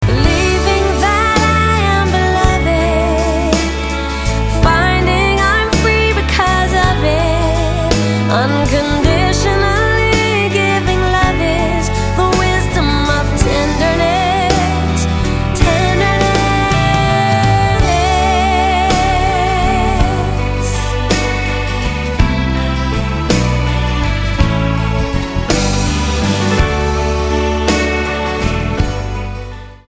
STYLE: Pop
smooth Anastasia-like vocals